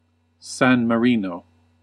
Ääntäminen
Ääntäminen US UK : IPA : /ˌsæn məˈɹi.nəʊ/ US : IPA : /ˌsæn məˈɹi.noʊ/ Haettu sana löytyi näillä lähdekielillä: englanti Käännös Erisnimet 1. San Marino {das} Määritelmät Erisnimet A country in Europe , located within the borders of Italy .